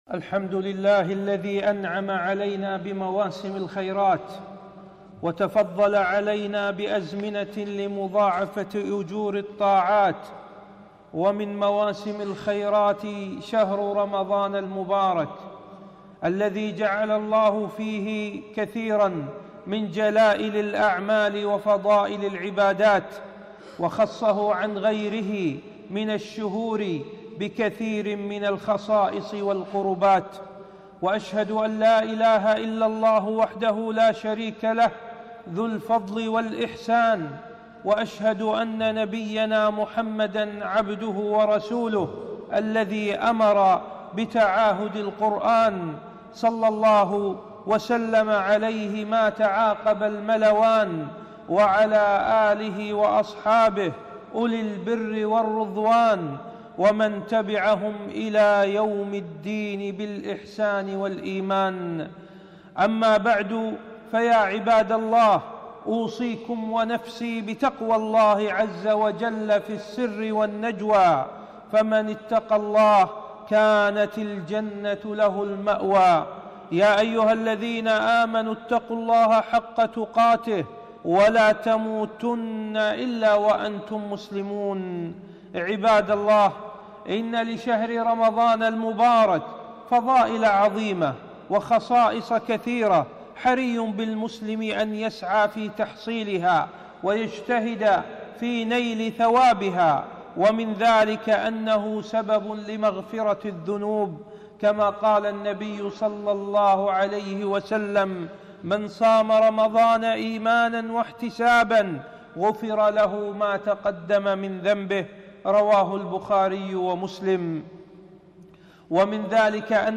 خطبة - دروس رمضانية